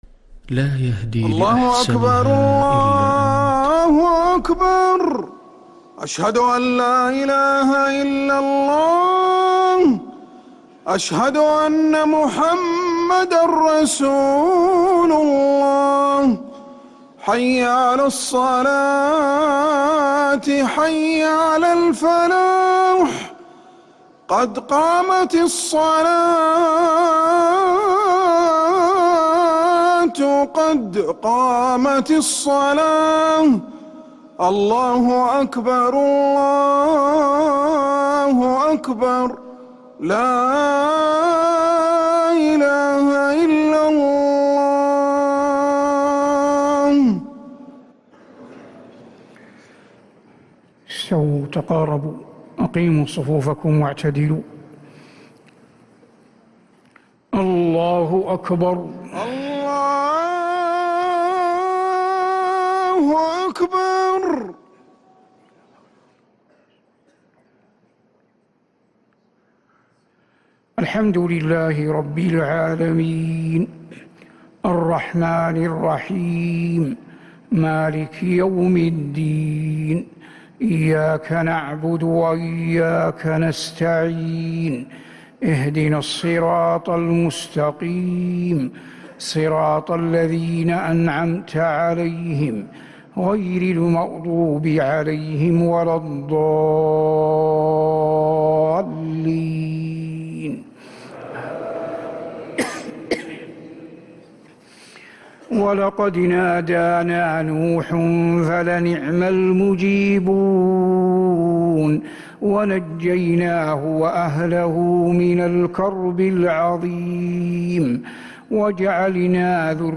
Haramain Salaah Recordings: Madeenah Fajr - 13th April 2026
Madeenah Fajr - 13th April 2026